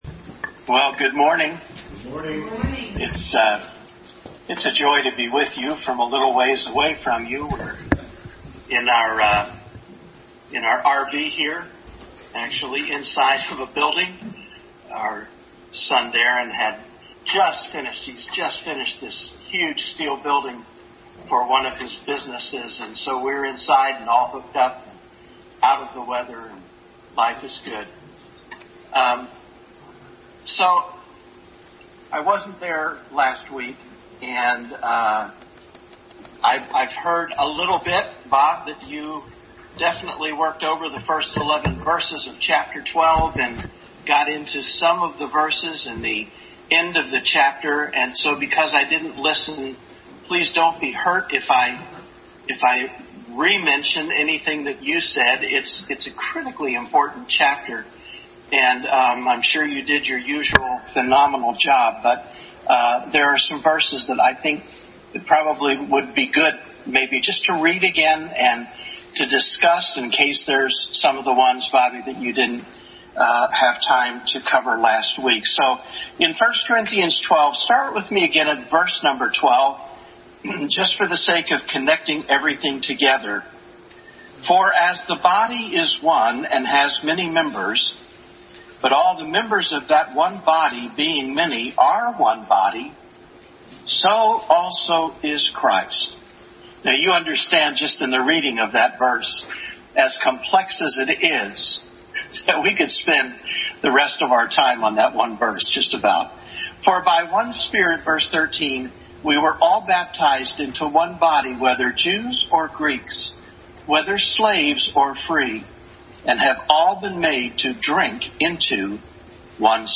Adult Sunday School Class.
Hebrews 12:12-17 Passage: Hebrews 12:12-17, Genesis 25:22-23, Malachi 1:2-3, Romans 9:10-13 Service Type: Sunday School Adult Sunday School Class.